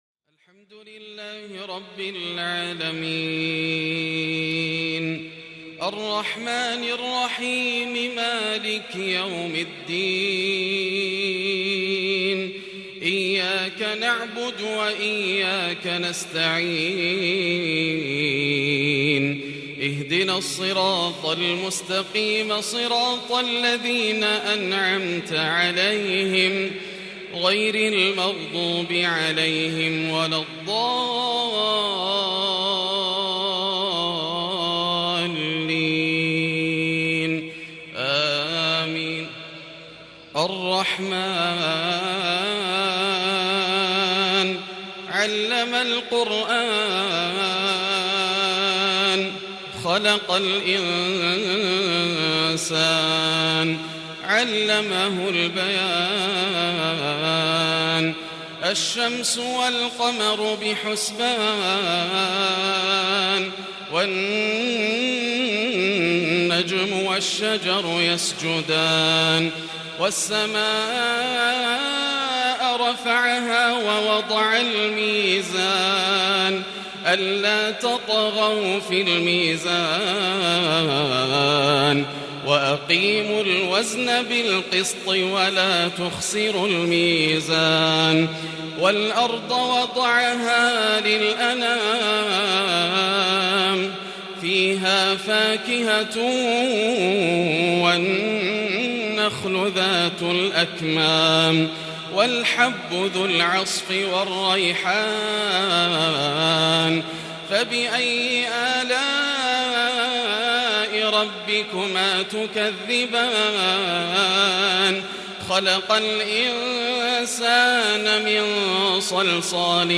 صلاة الفجر الأحد ٤-١-١٤٤٢هـ من سورة الرحمن | Fajr prayer from Surat Al-Rahman | 23/8/2020 > 1442 🕋 > الفروض - تلاوات الحرمين